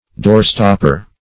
\door"stop*per\